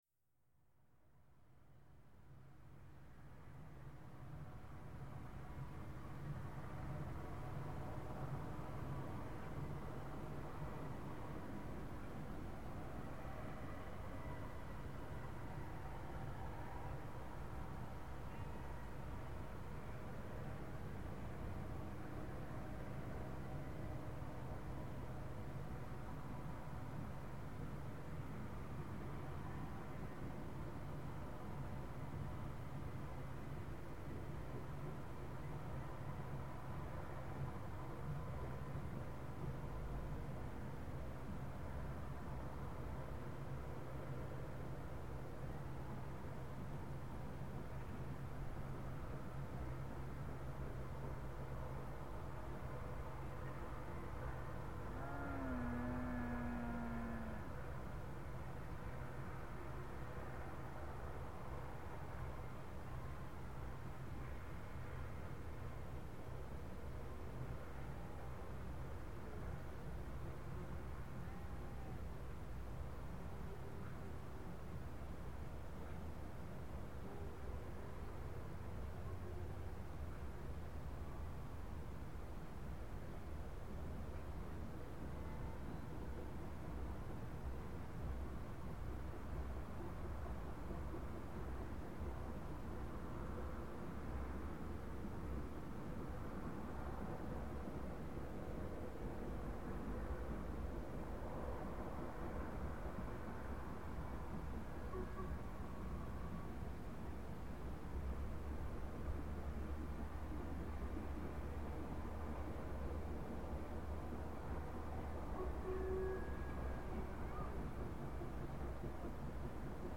Selbstverständlich nun die folgende synchrone Tonaufnahme:
44767 mit Zug nach Pickering bei Green End, um 14:58h am 08.08.2000.
Extrem laut und hammerhart, besser... geht das auch mit der Lok nicht mehr, hat meiner Meinung nach wirklich den härtesten Sound bekannter Europäischer Dampfloks, oder?